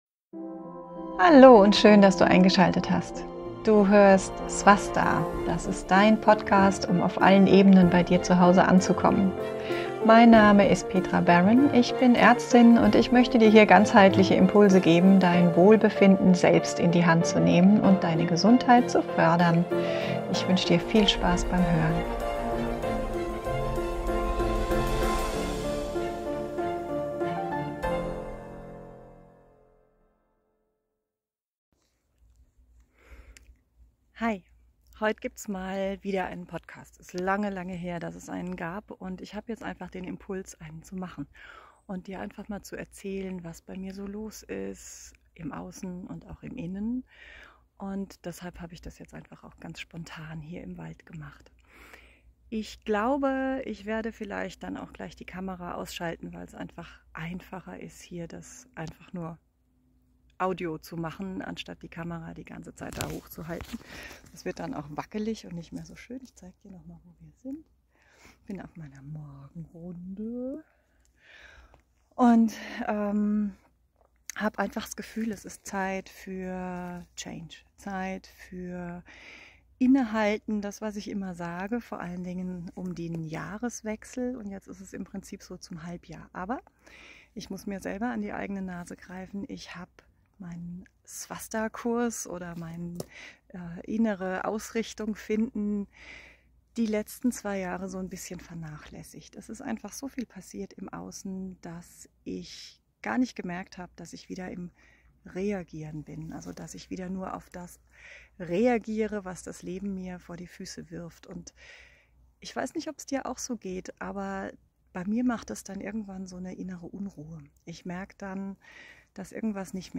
Mein Gastinterview bei den Newmoon Talks der Jadeflower Academy